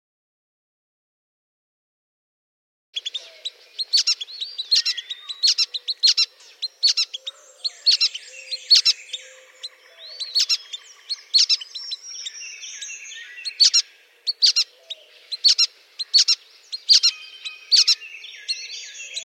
The voice is larger-than-life too, often sounding remarkably like an oversized rubber ducky.
brown-headed-nuthatch.mp3